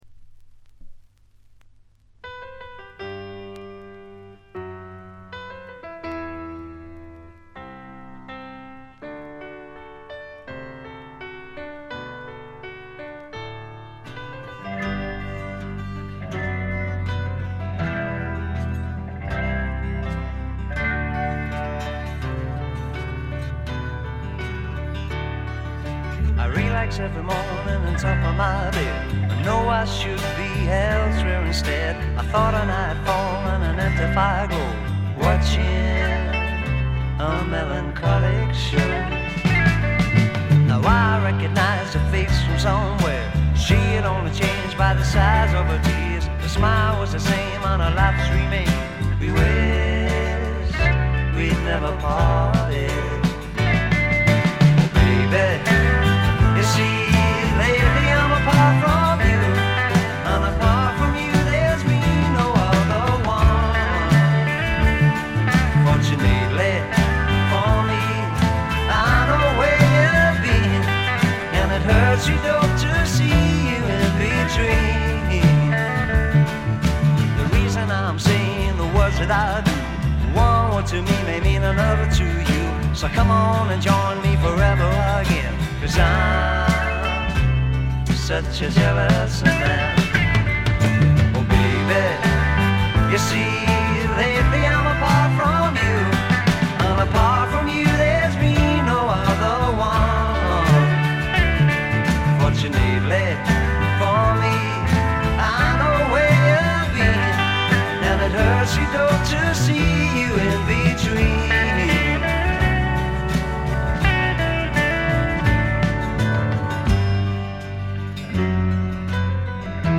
ごくわずかなノイズ感のみ。
内容は1971年という時代背景にあって典型的かつ最良の英国流フォーク・ロックです。
試聴曲は現品からの取り込み音源です。